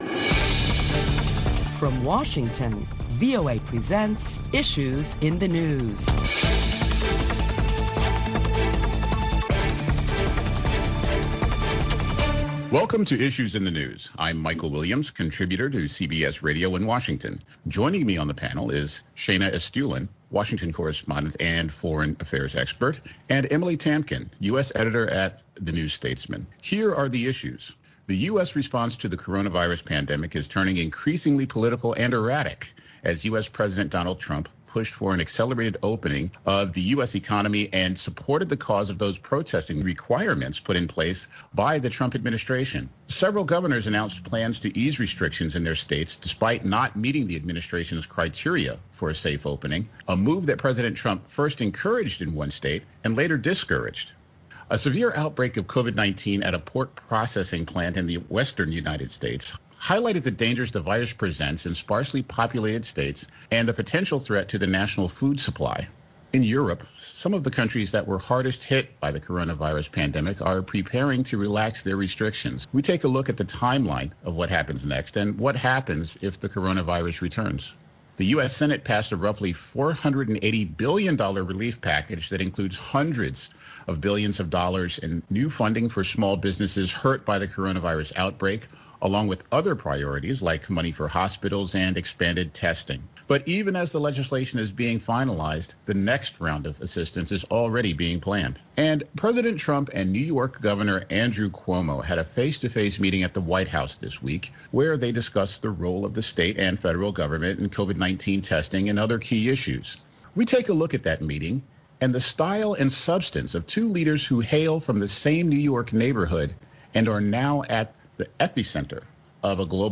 Listen to a panel of prominent Washington journalists as they deliberate the latest top stories of the week which include Americans in several states protest to reopen their states for business as COVID-19 has claimed over 50,000 American lives.